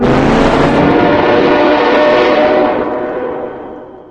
razortrainHorn.ogg